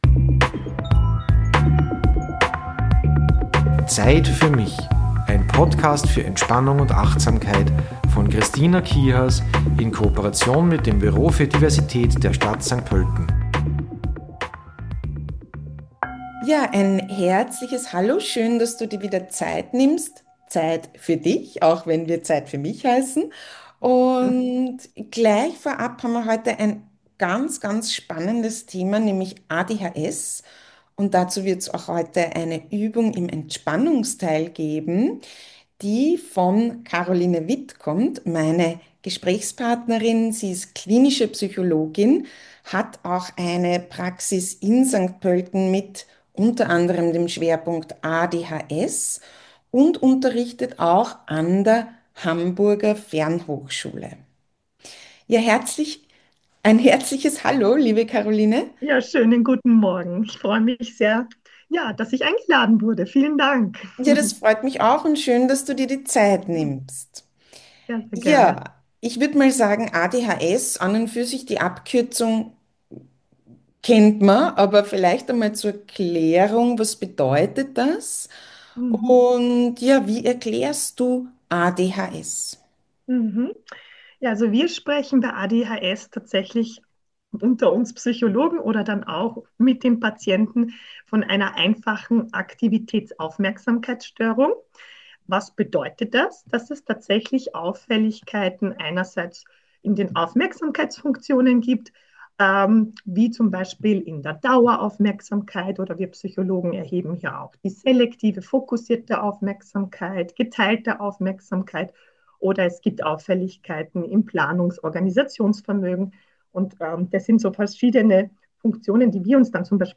Auch eine Übung erwartet dich: die 5 - 4 - 3 - 2 - 1 Achtsamkeitsmethode.